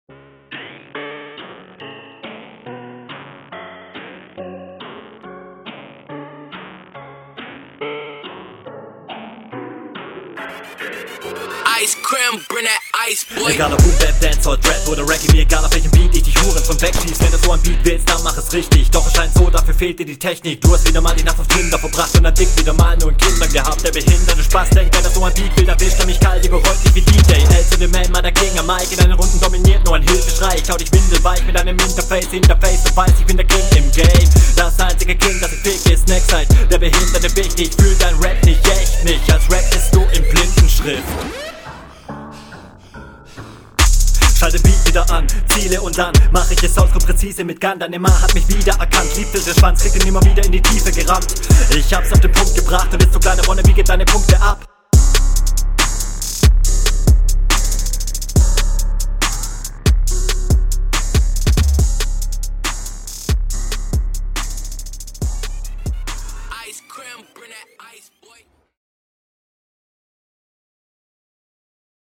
Flowliche Variationen sind mega.